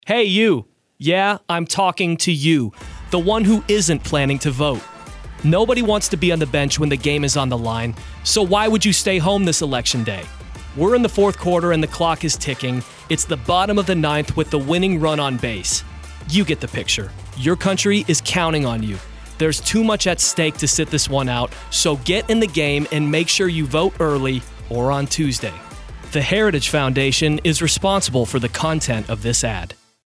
That’s why The Heritage Foundation is releasing two public service announcements encouraging all Americans to vote this Election Day.